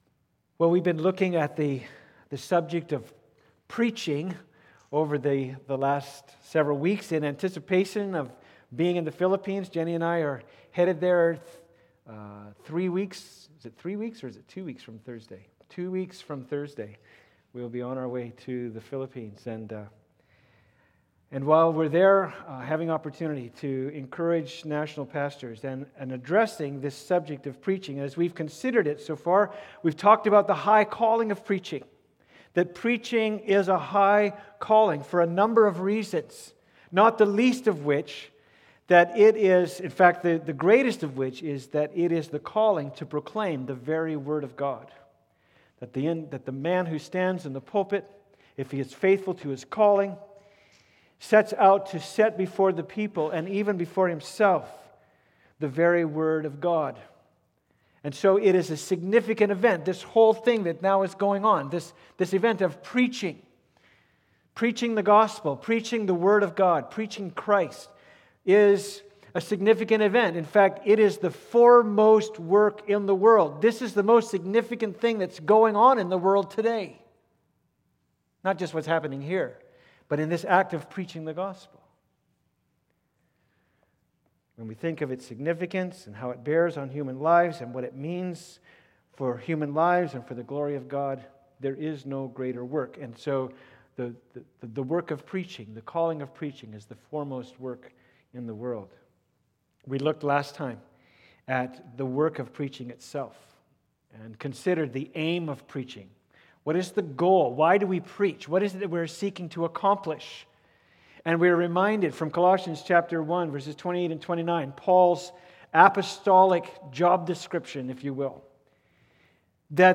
Sermon 9-20